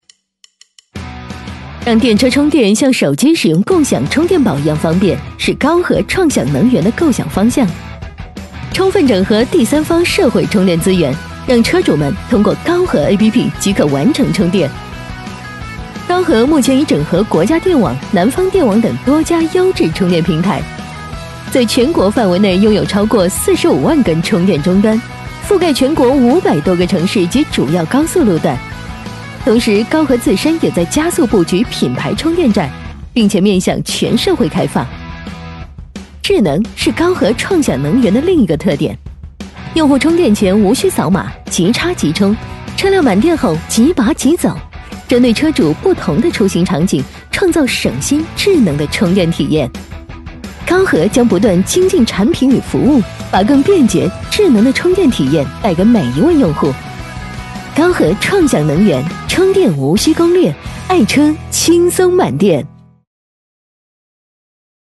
女97-广告 - 科技新能源-高和汽车
女97全能配音 v97
女97-广告---科技新能源-高和汽车.mp3